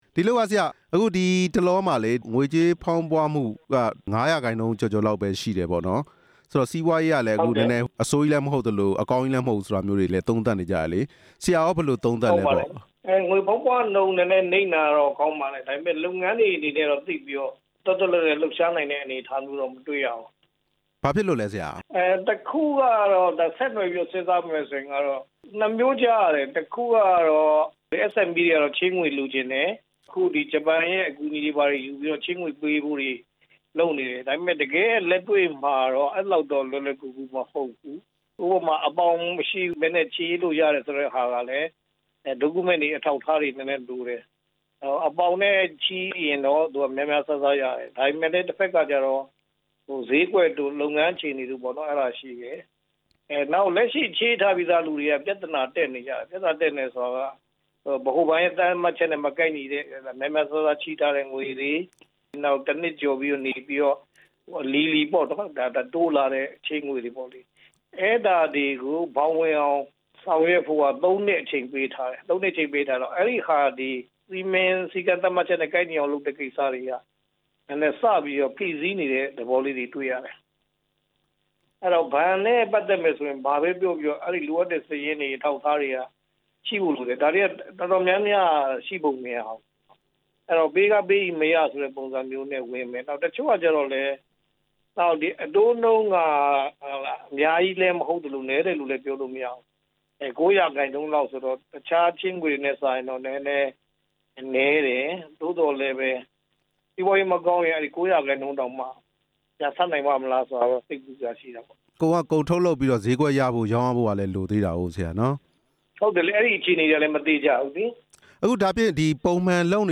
နဲ့ ဆက်သွယ်မေးမြန်းချက်